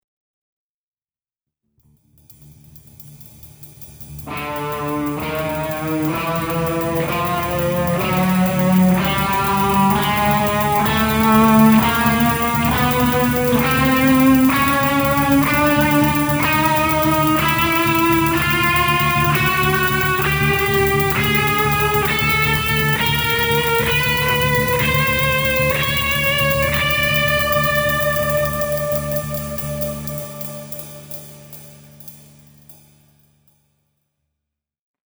Now let’s apply the same concept to the chromatic scale, depicted in FIGURE 1c.